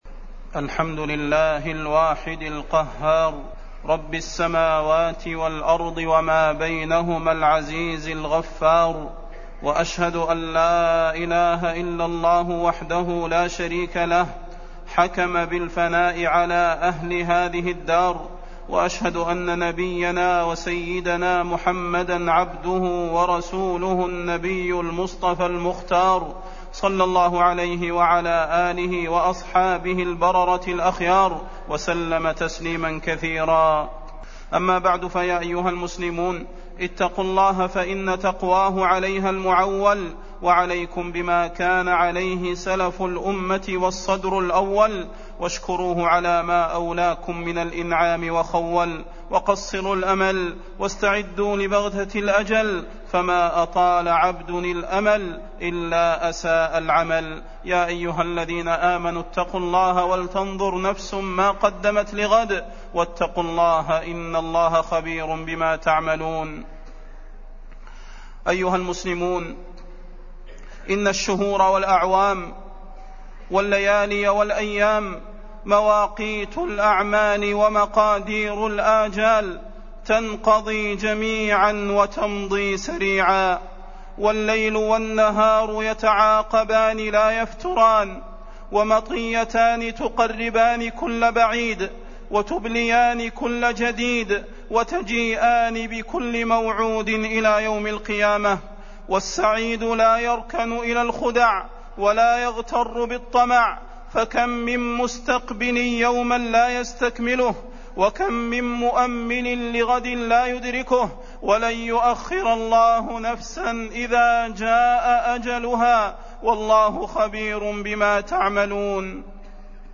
فضيلة الشيخ د. صلاح بن محمد البدير
تاريخ النشر ٧ محرم ١٤٢٨ هـ المكان: المسجد النبوي الشيخ: فضيلة الشيخ د. صلاح بن محمد البدير فضيلة الشيخ د. صلاح بن محمد البدير إن الأجل لآت